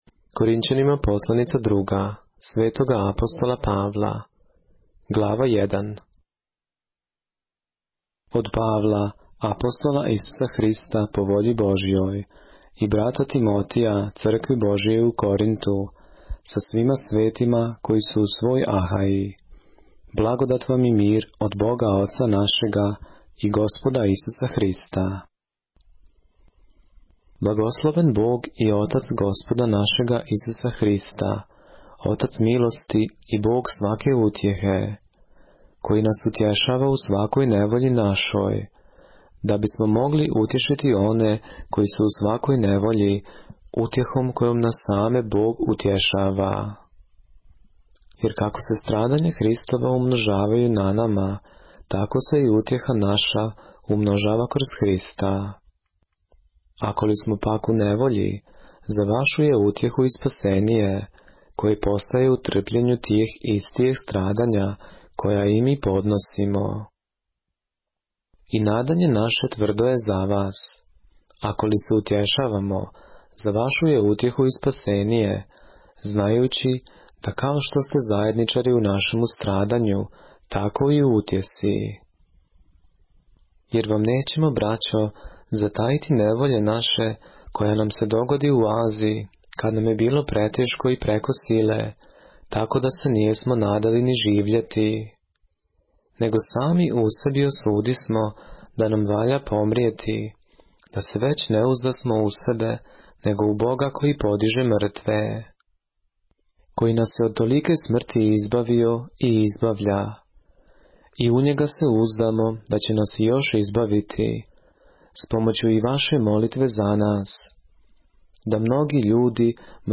поглавље српске Библије - са аудио нарације - 2 Corinthians, chapter 1 of the Holy Bible in the Serbian language